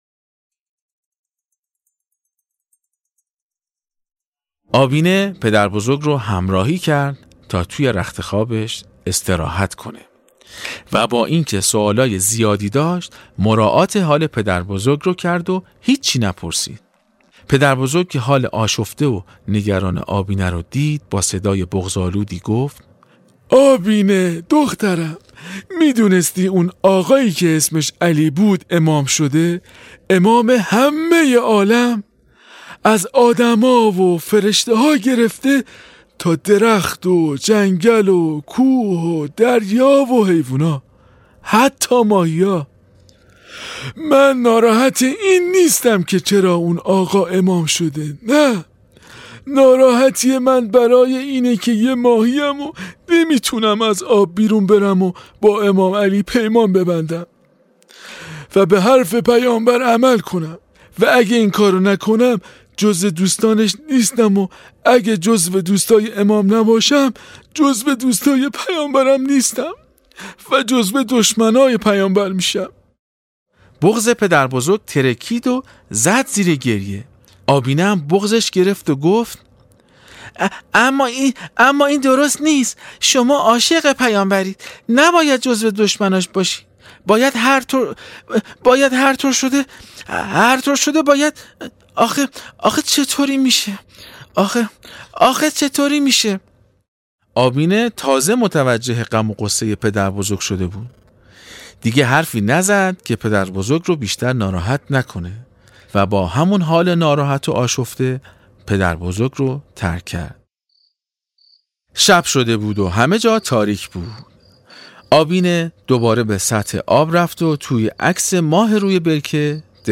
داستان ماهی ای که از یه جریان خیلی مهم برامون صحبت میکنه. باهم قسمت چهارم از داستان صوتی آبینه رو بشنویم.